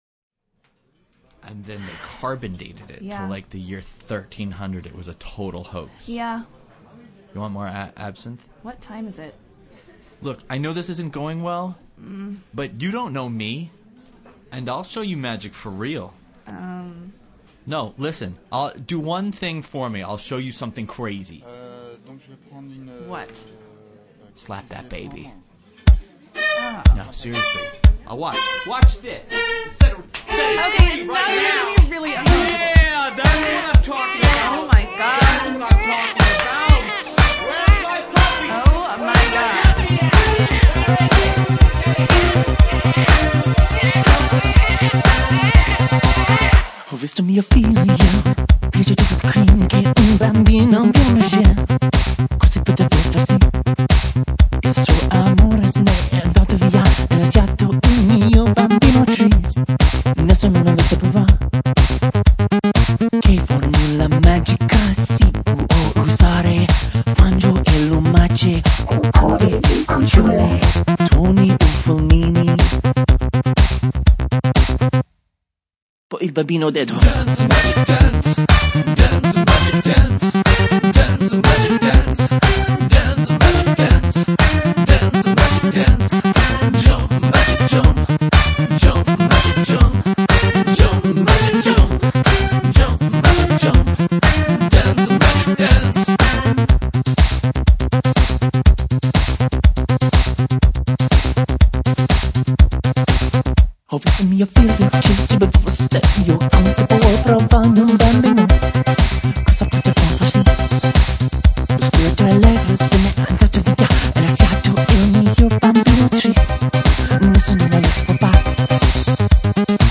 includes the spoken-word intro.